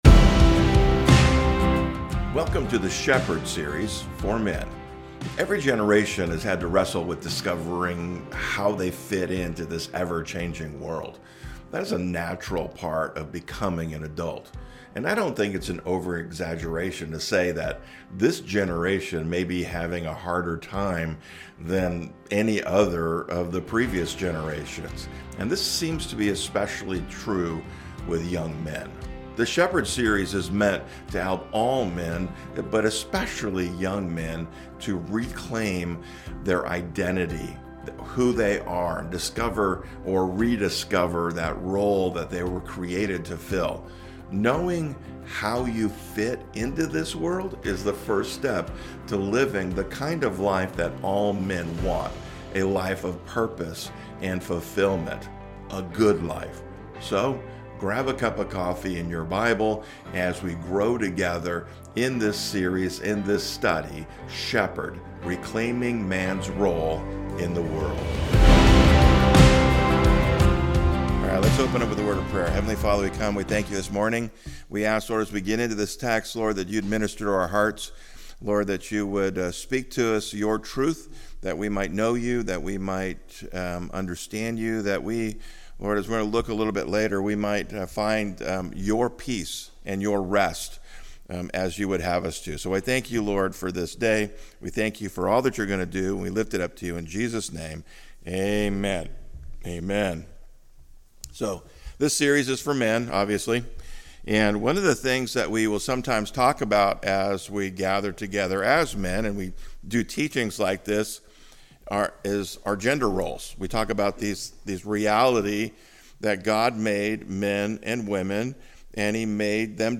SHEPHERD is a series of messages for men.